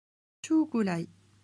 Ecoutez comment on dit certains mots de l’histoire en japonais: